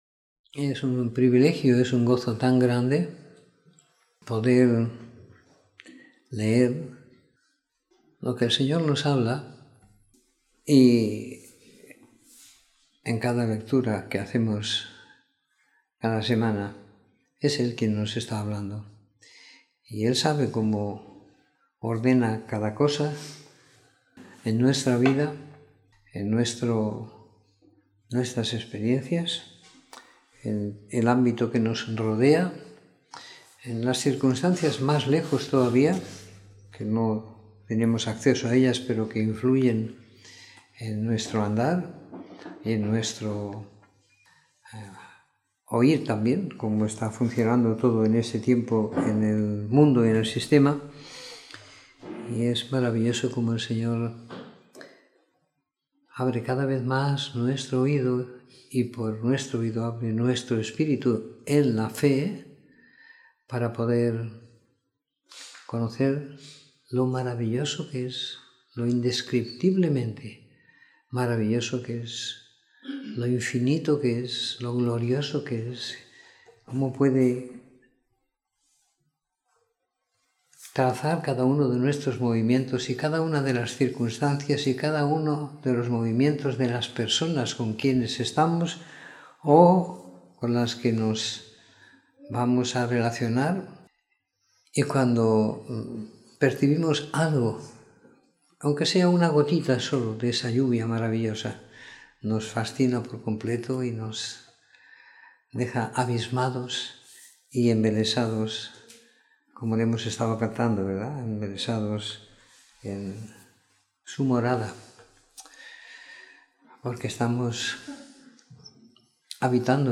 Comentario en el evangelio de Juan del capítulo 11 al 21 siguiendo la lectura programada para cada semana del año que tenemos en la congregación en Sant Pere de Ribes.